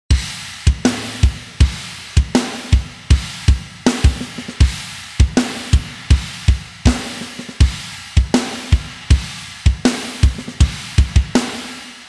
サウンドデモ
ドラム（SA-3適用後）
SA-3_Drums_Engaged.wav